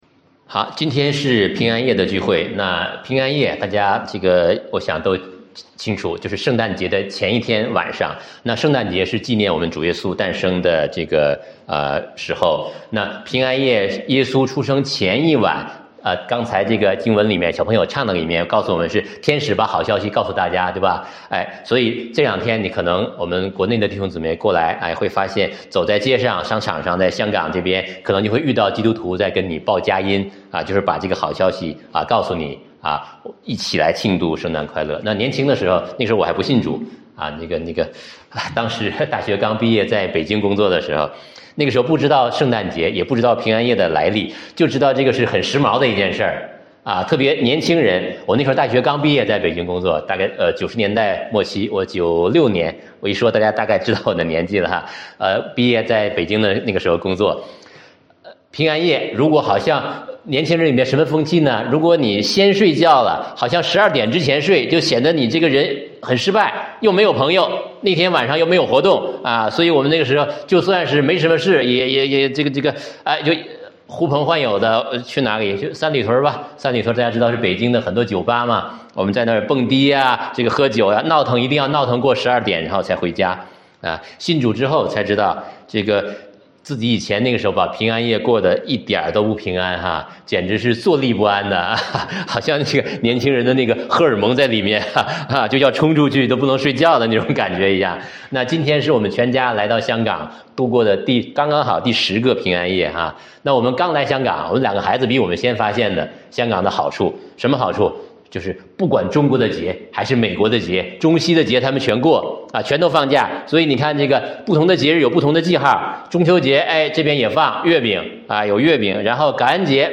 平安夜聚会：认出你的记号-压缩.mp3